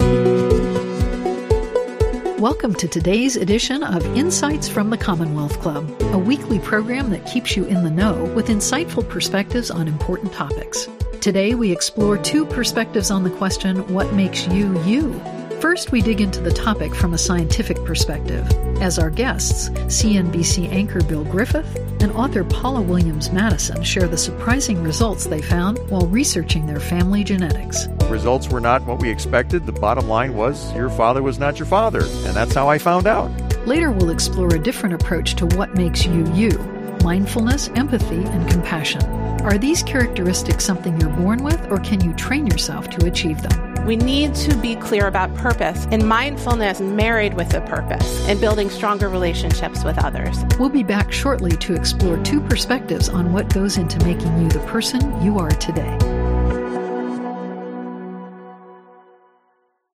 Ep001 Segment A. 1-min Billboard